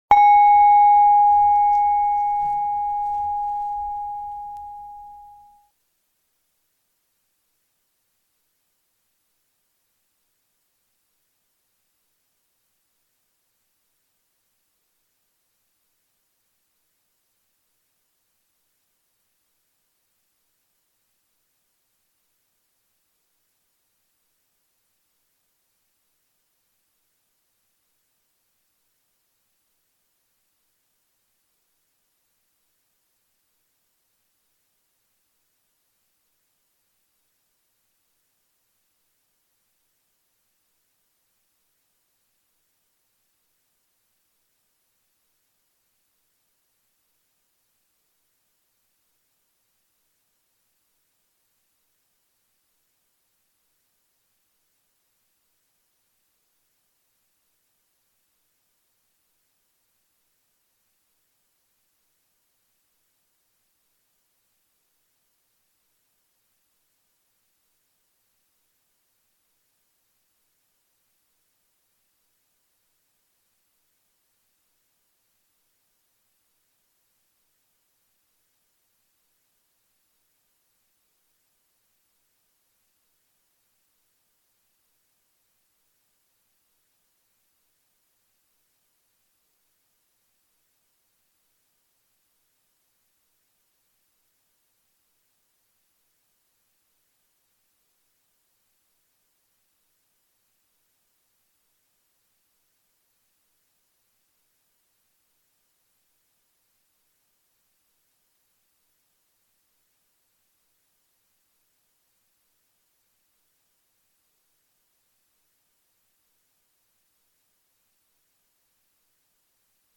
silence-total-jusqua-60